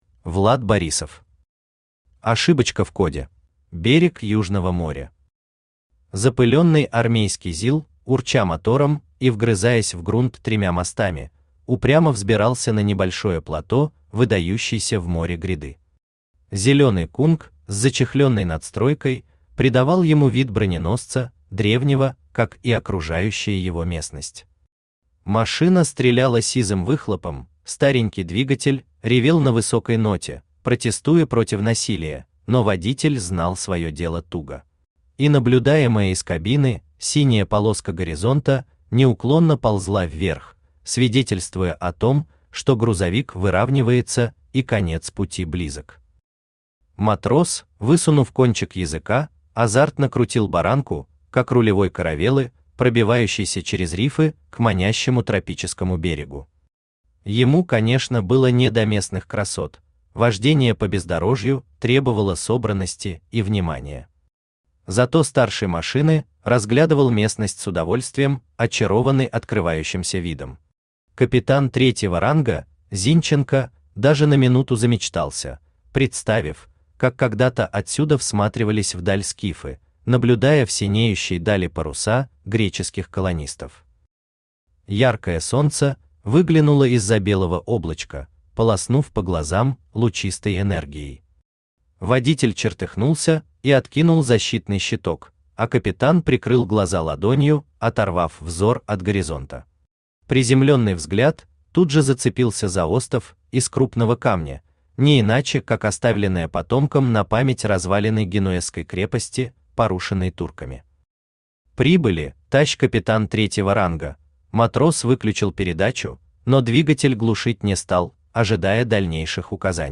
Аудиокнига Ошибочка в коде | Библиотека аудиокниг
Aудиокнига Ошибочка в коде Автор Влад Борисов Читает аудиокнигу Авточтец ЛитРес.